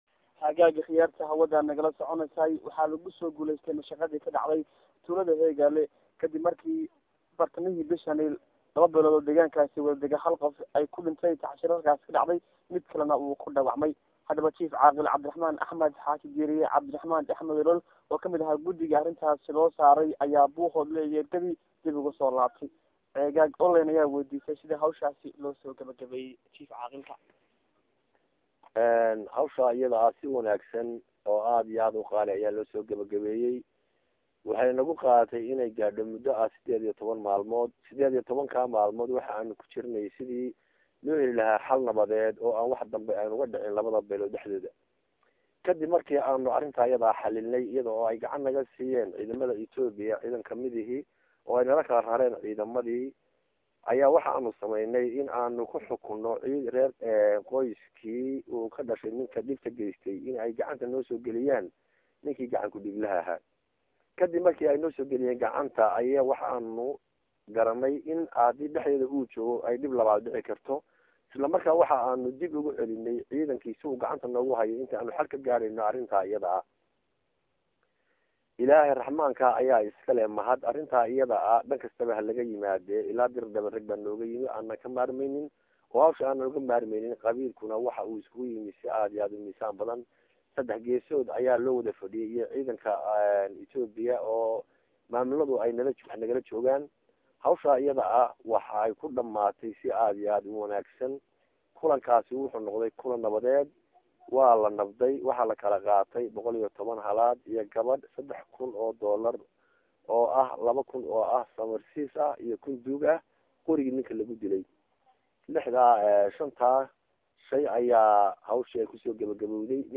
Mashaqadii ka dhacday Heegaale oo lagu guulaysay (Wareysi